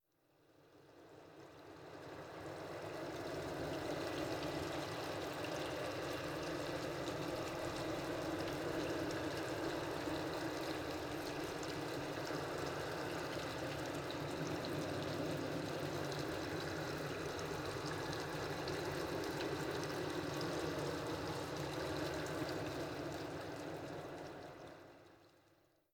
Efecto de lluvía nuclear
lluvia
Sonidos: Especiales